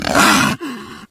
flesh_pain_2.ogg